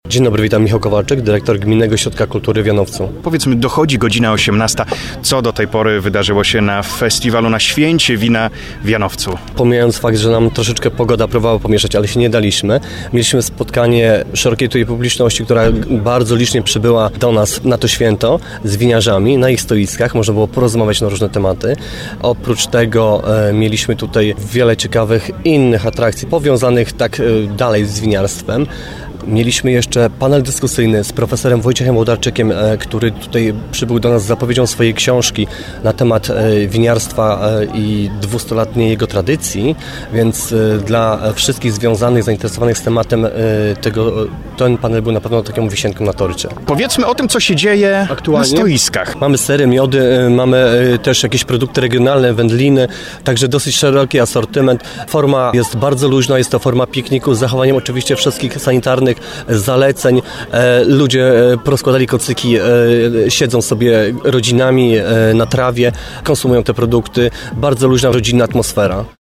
W sobotę, na rozmowę z uczestnikami festiwalu, wybrał się nasz reporter.